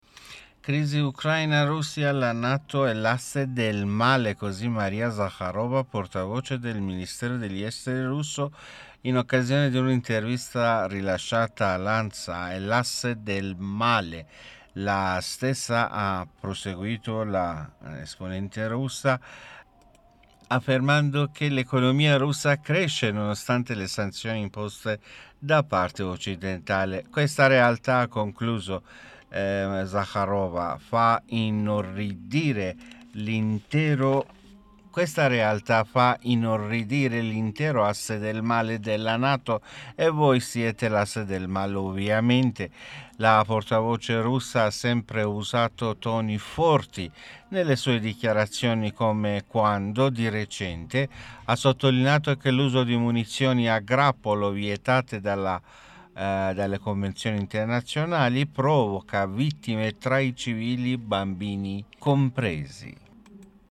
MOSCA - Così Maria Zakharova, portavoce del ministero degli Esteri russo, in occasione di una intervista rilasciata ad Ansa.